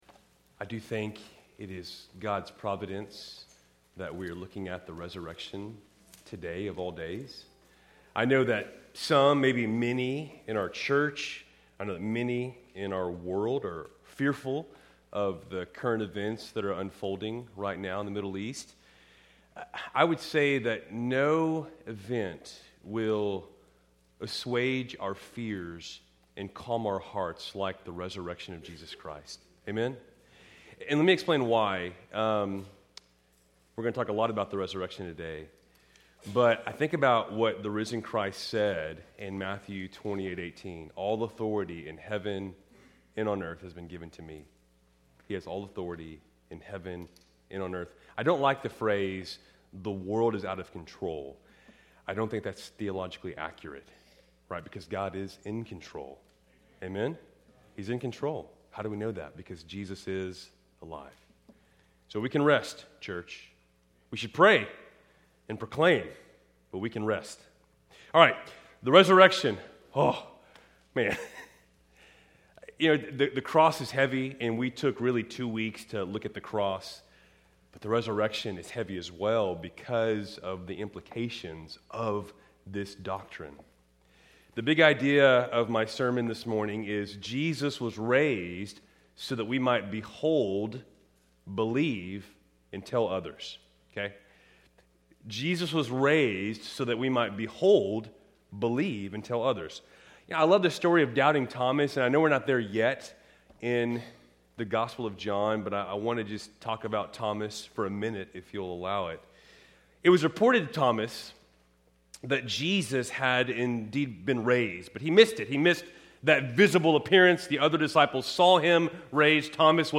Keltys Worship Service, March 1, 2026